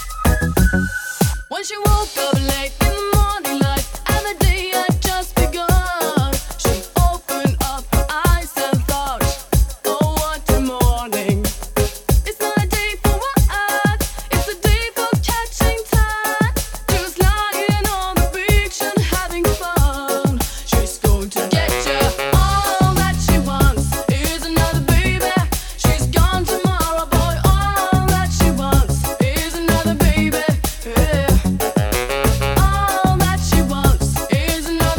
Жанр: Поп музыка / Танцевальные / Электроника